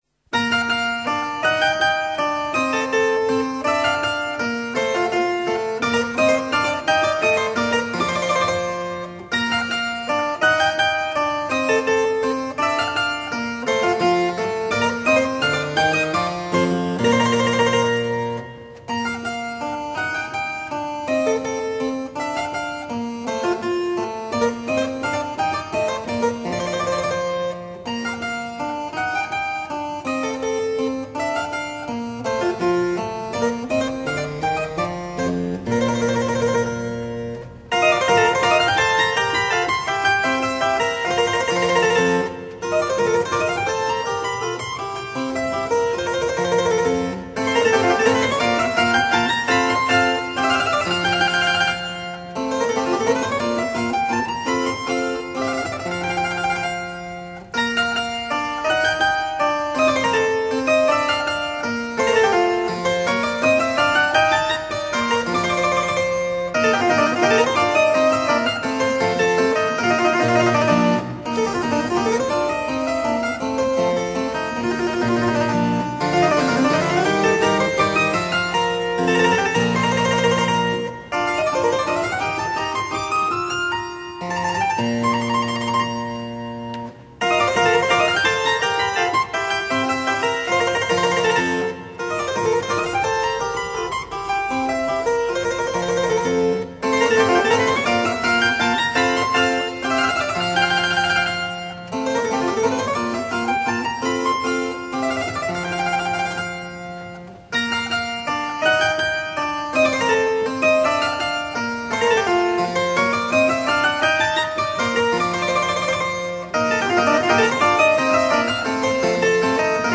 Sonate pour clavecin Kk 440 : Rondo